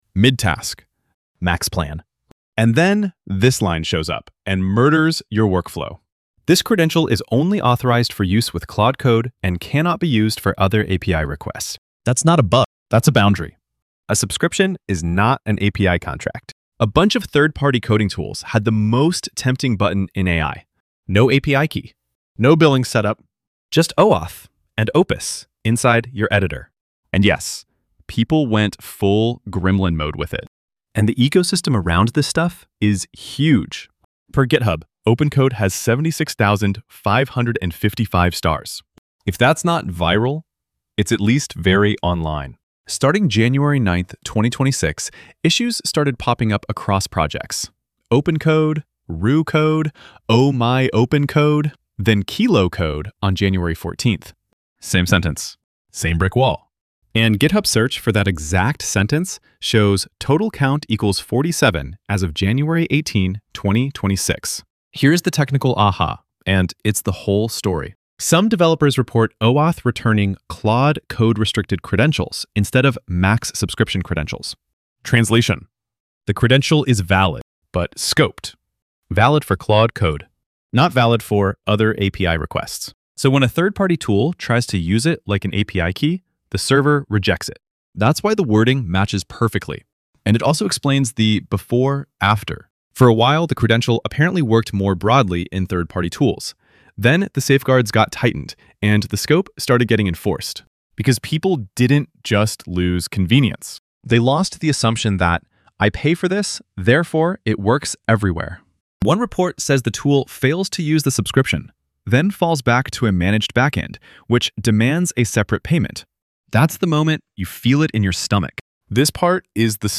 Voiceover
Generated VO from the script (ElevenLabs).
Running 25 WPM too slow for Fireship energy at 165 WPM (target 190-220).
Several mid-script segments drag with unnecessary specificity and attribution bloat.